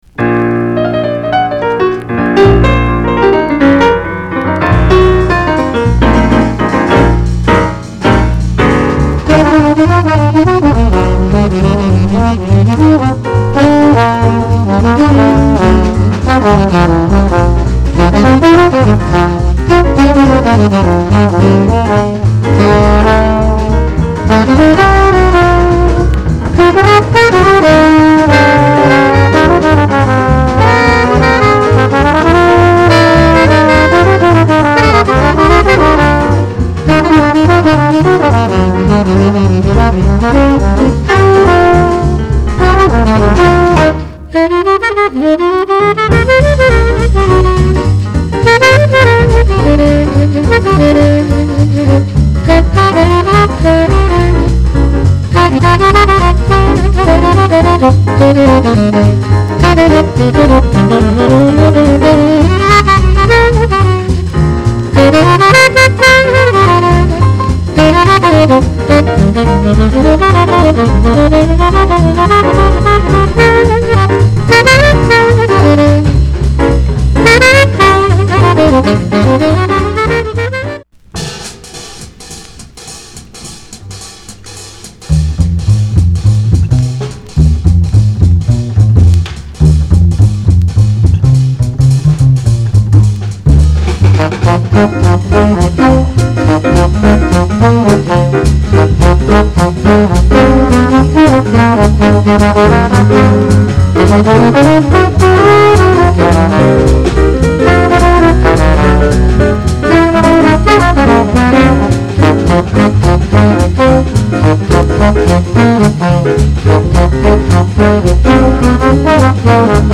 discription:Mono両溝
Format：LP